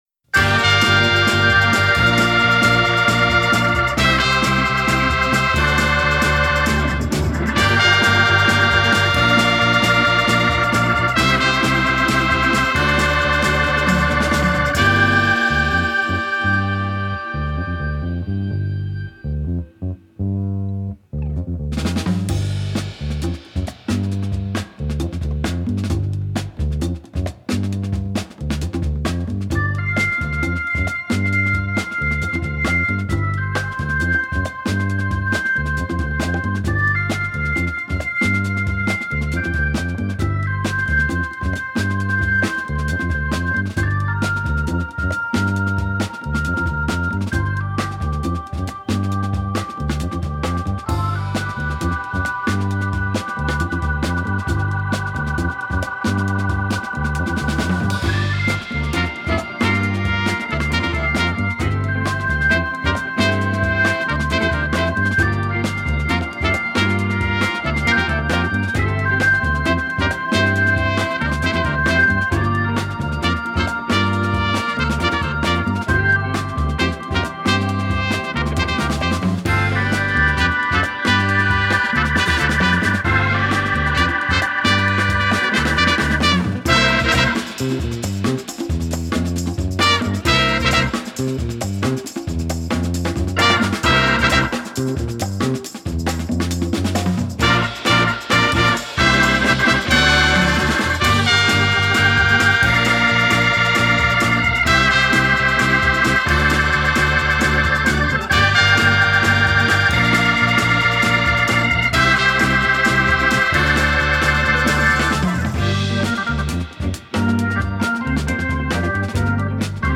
guitar playing
KICKS from start to finish
The Tune is a rendition of the theme tune from seminal